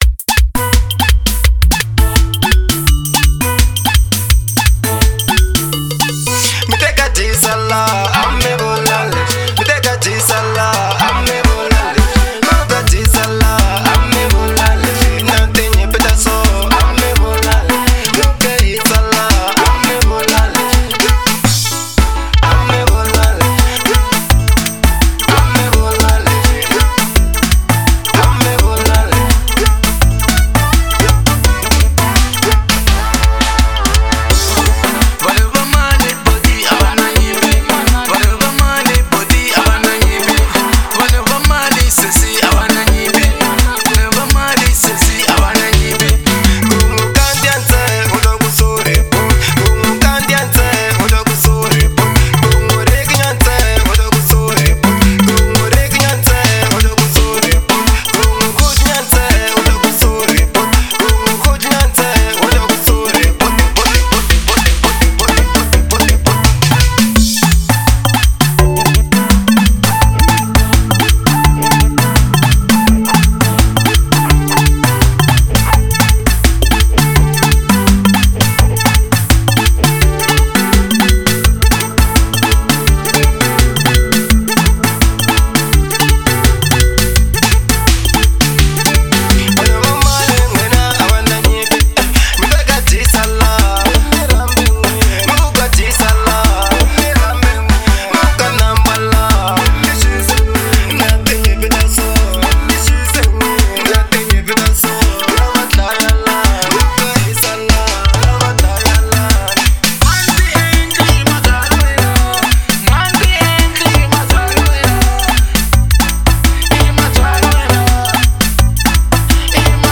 03:42 Genre : Xitsonga Size